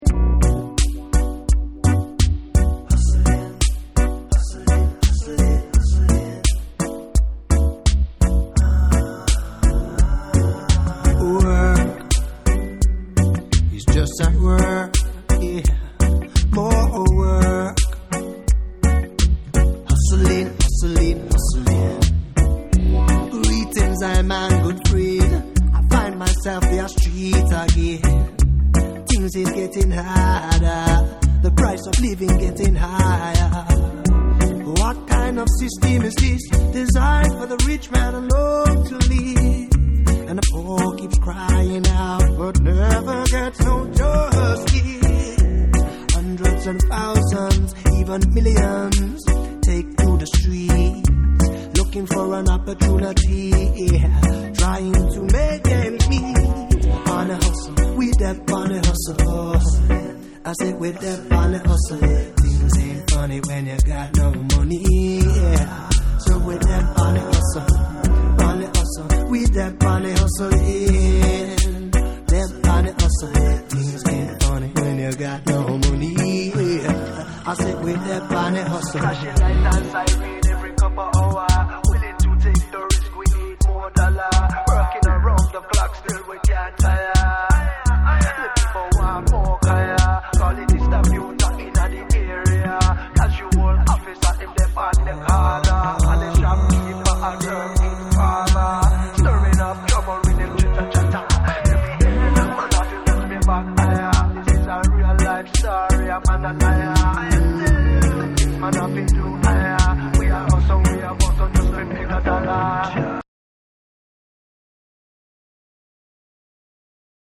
ファルセット・ボイスが特徴的なレゲエ・シンガー
ダブ・ブレイクビーツとしても楽しめる
REGGAE & DUB / ROOTS & CULTURE